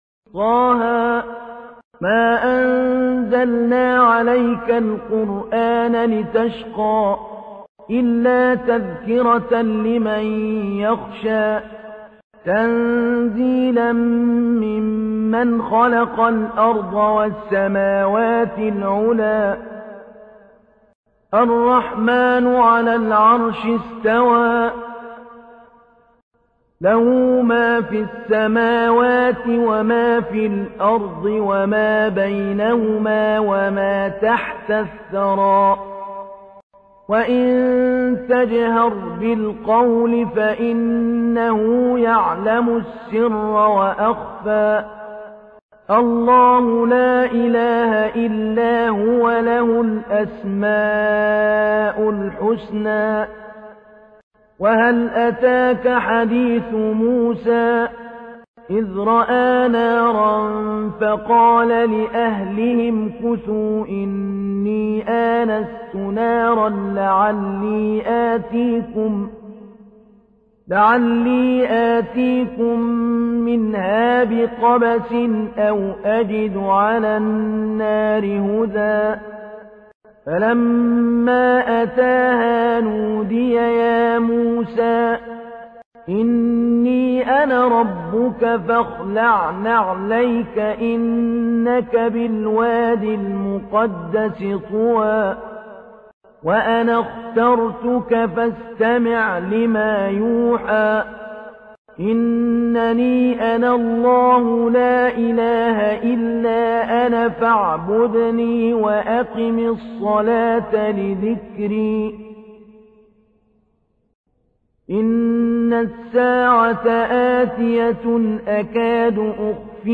تحميل : 20. سورة طه / القارئ محمود علي البنا / القرآن الكريم / موقع يا حسين